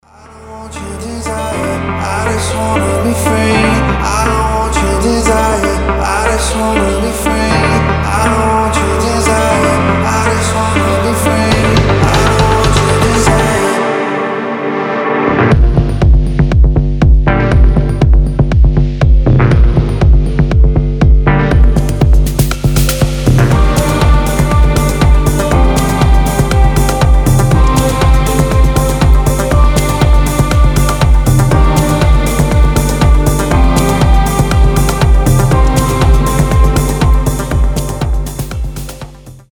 • Качество: 320, Stereo
deep house
атмосферные
красивый мужской голос
Electronic
Downtempo
басы
deep progressive